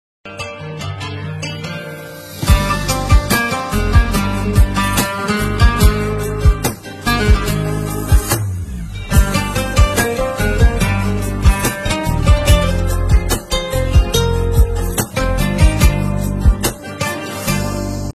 ژانر: بی کلام
آهنگ زنگ تماس(شیک و خوشگل)